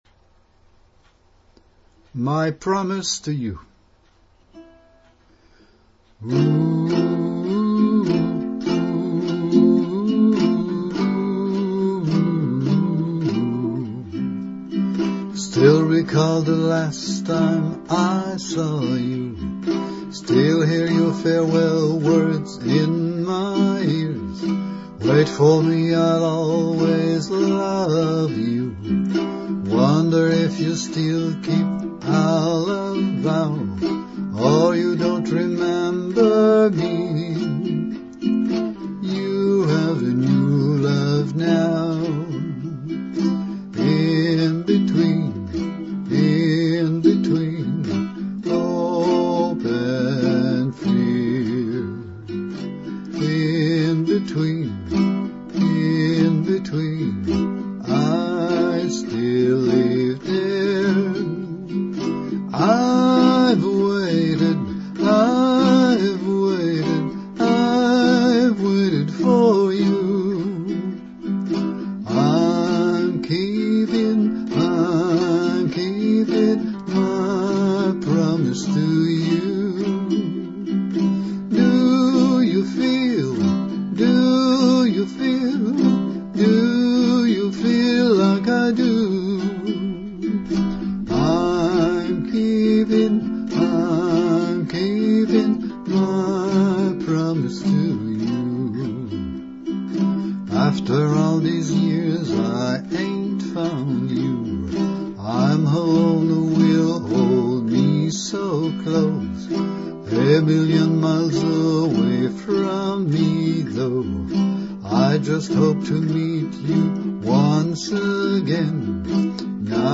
" My Promise " Ballad song
(my voice + ukulele)
Intro: 4 bars (key of C)